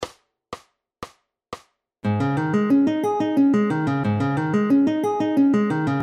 C♯sus2 トライアド
コンテンポラリー,ジャズギター,トライアド,sus2,アドリブ